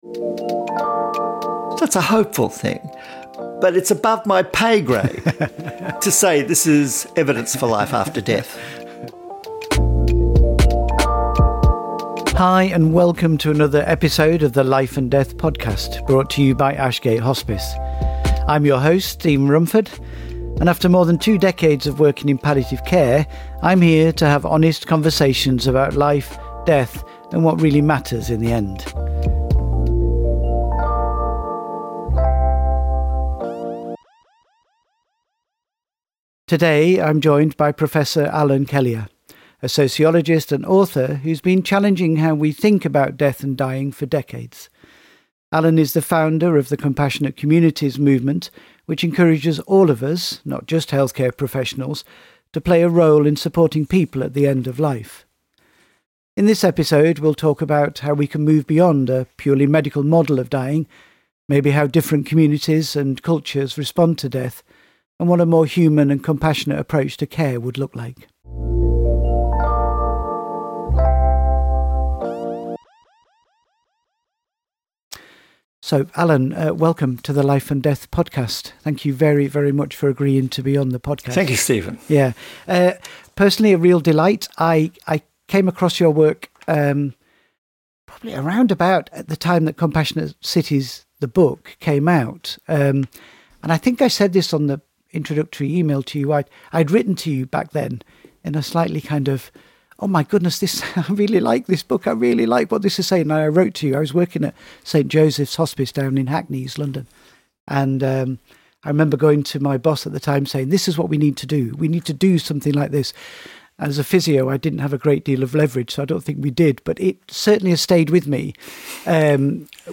This is a conversation about presence, belonging, and why we need a more compassionate, inclusive approach to death, dying and grief.